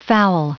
Prononciation du mot foul en anglais (fichier audio)
Prononciation du mot : foul